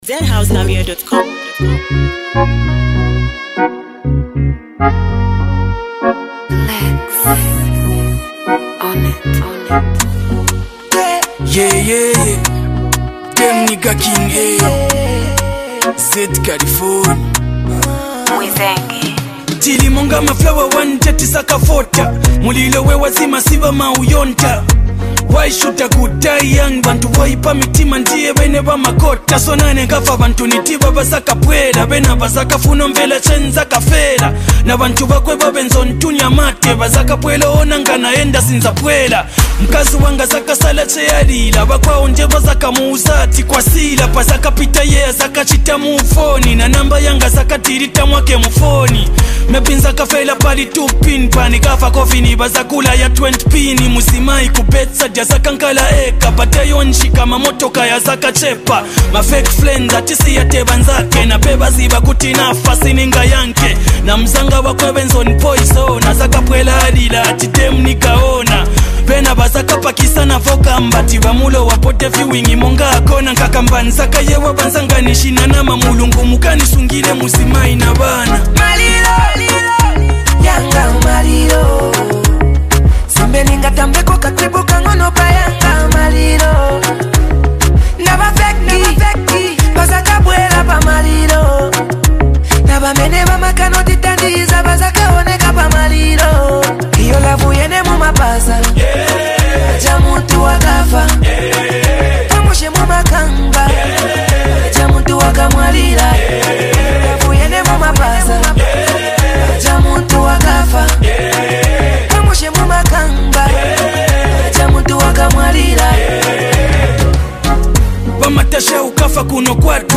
a conscious track that reflects on life